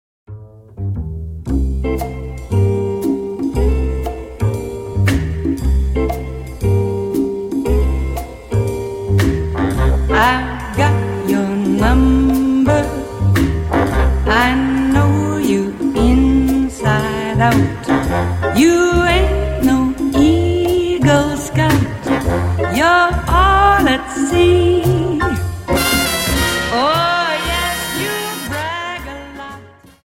Slowfox 29 Song